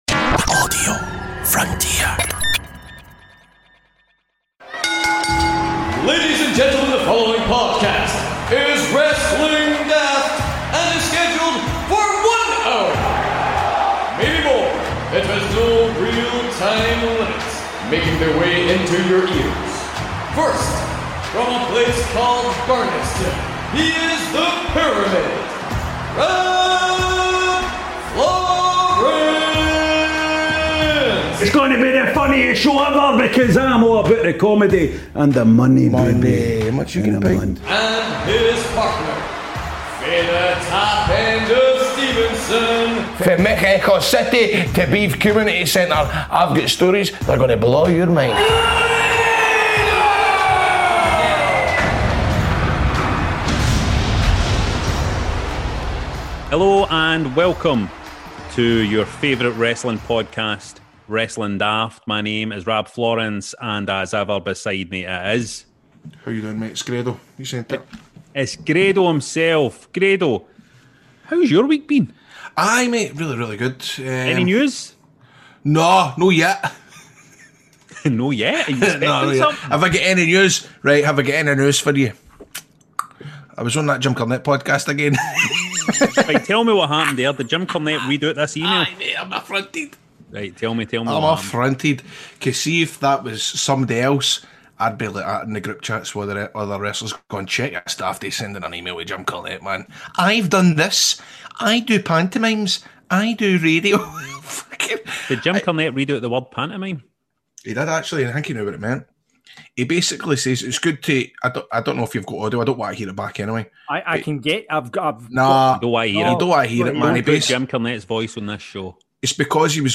chat to MP Mark Fletcher, who spoke about the future of the business in the House of Commons.